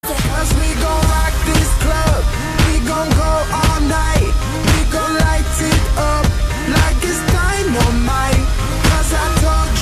306-dynamite.mp3